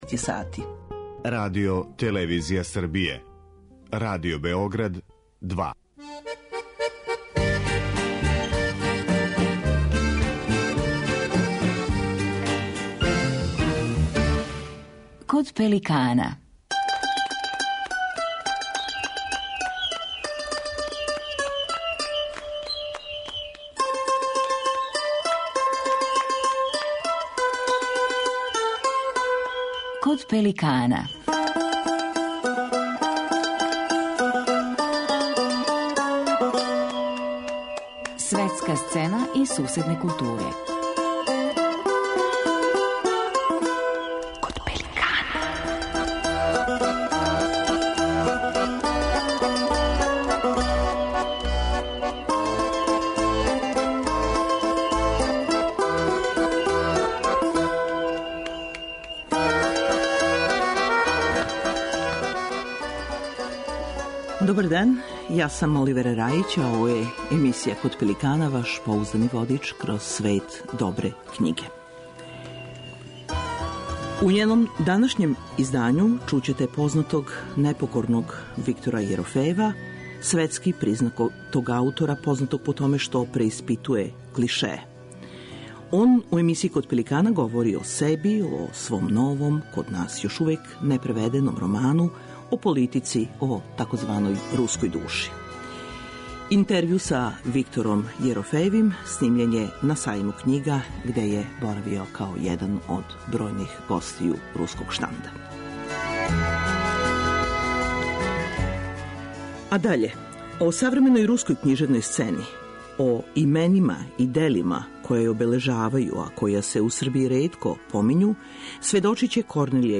Познати "непокорни" Виктор Јерофејев, светски признат аутор који преиспитује клишее, говори у емисији Kод пеликана о себи, о свом новом, код нас још непреведеном роману, о политици, о тзв. "руској души"... Интервју са Виктором Јерофејевим снимљен је на Сајму књига.